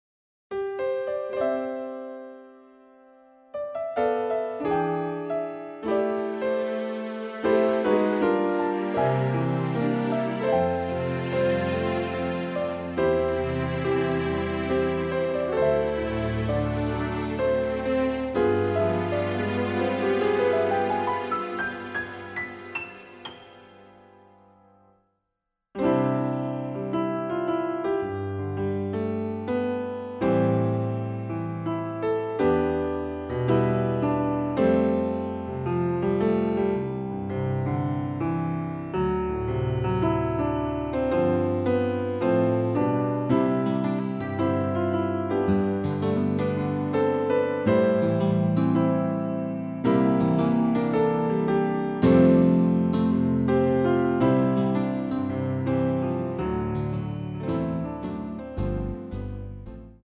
*부담없이즐기는 심플한 MR~
◈ 곡명 옆 (-1)은 반음 내림, (+1)은 반음 올림 입니다.
앞부분30초, 뒷부분30초씩 편집해서 올려 드리고 있습니다.
중간에 음이 끈어지고 다시 나오는 이유는